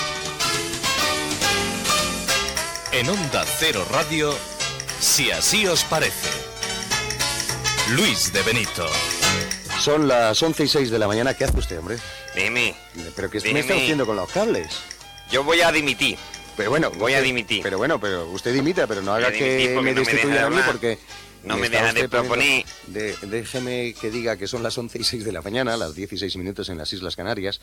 Identificació del programa, salutació a la imitació del polític Alfonso Guerra i hora
Entreteniment